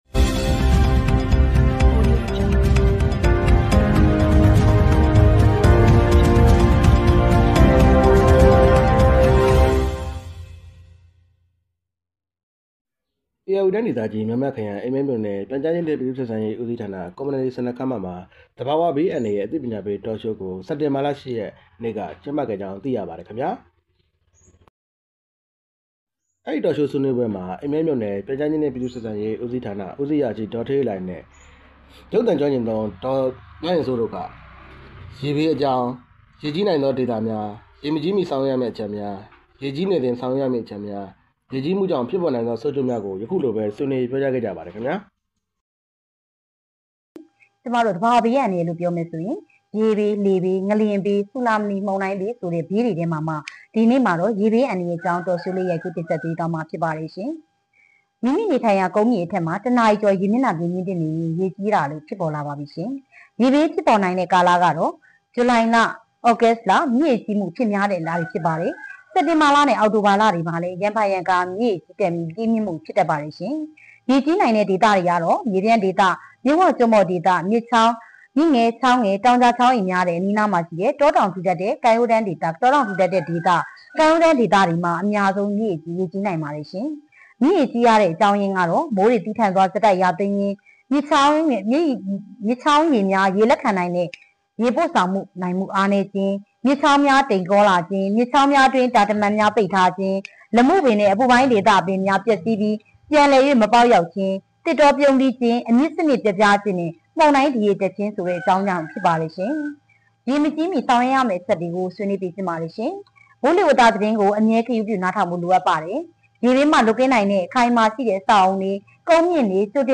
အိမ်မဲမြို့နယ် Community Centre ခန်းမတွင် သဘာဝဘေးအန္တရာယ်ဆိုင်ရာ အသိပညာပေး Talk...